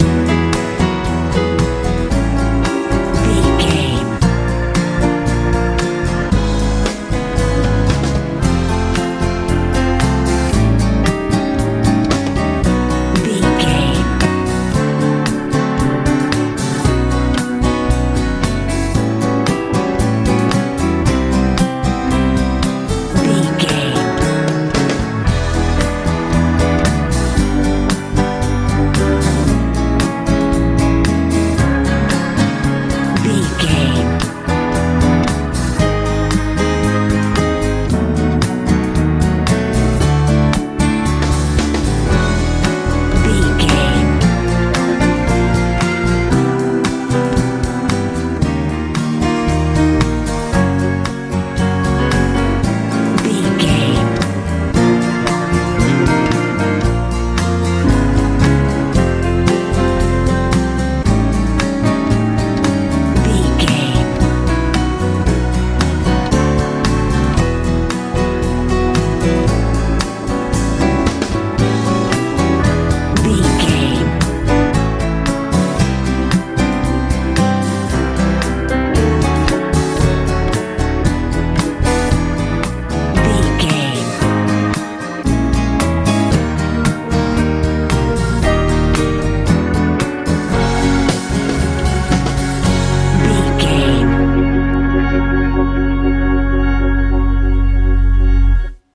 groovey pop feel
Ionian/Major
hopeful
joyful
piano
drums
acoustic guitar
organ
bass guitar
sweet
soft
bright